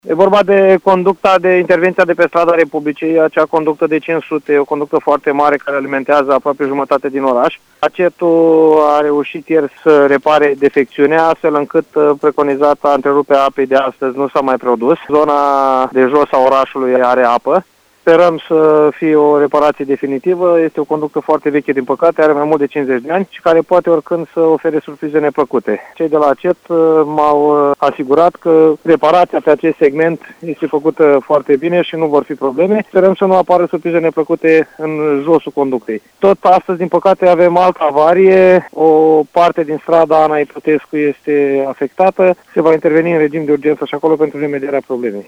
În zona casieriei UPC s-a produs o avarie, dar situația revine la normal, după cum a precizat, pentru VIVA FM, primarul de Fălticeni, Cătălin Coman.